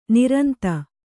♪ niranta